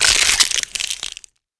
rifle_first_open.wav